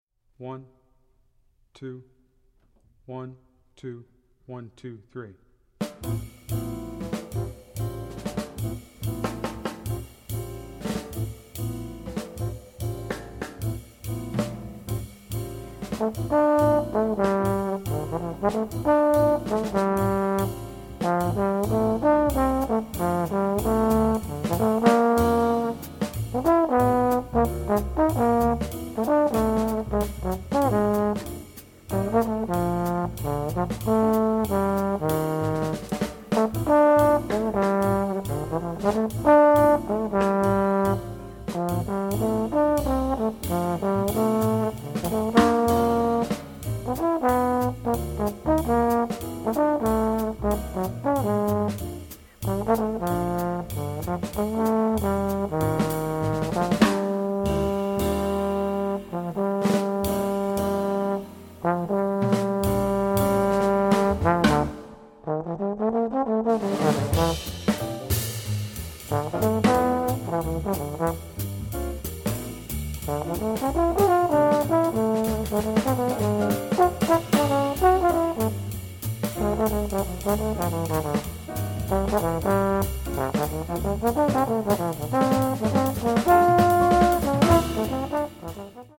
Voicing: Jazz Tromb